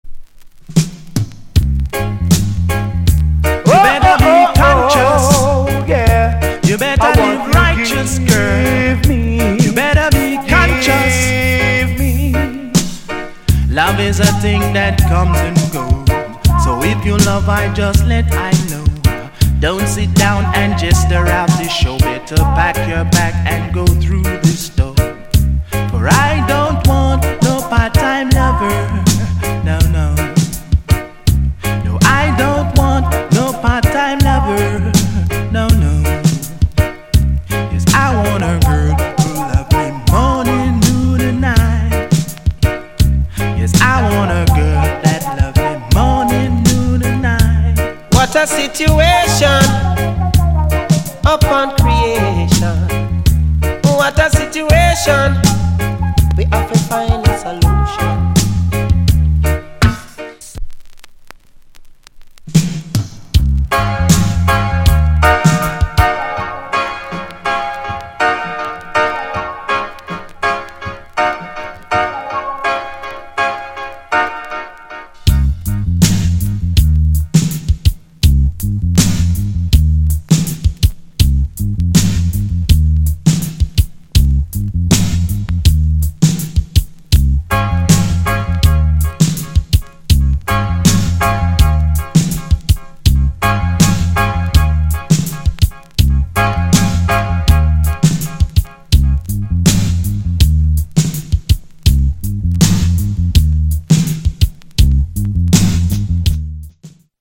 * '86 good vocal Tune!!